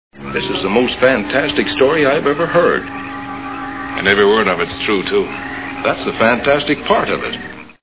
Plan 9 From Outer Space Movie Sound Bites